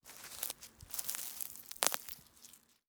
SFX_Harvesting_03.wav